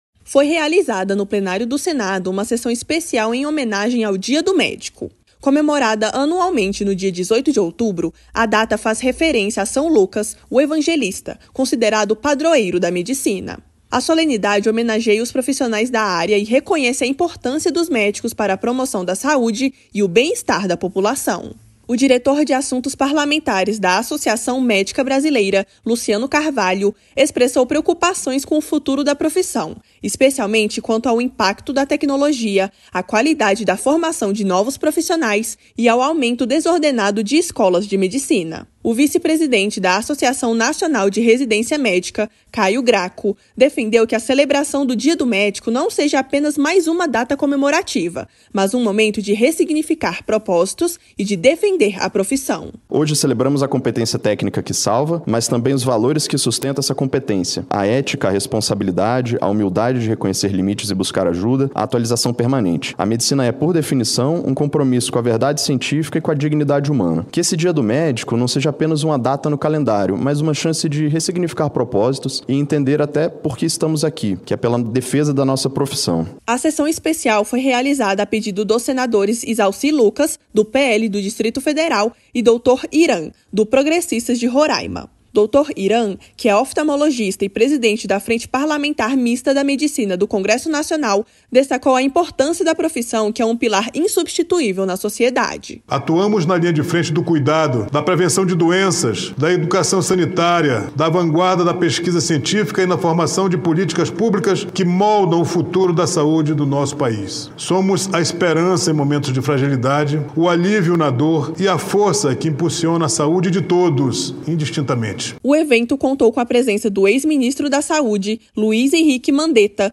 O Dia do Médico, comemorado no dia 18 de outubro, foi celebrado em sessão especial no Plenário do Senado.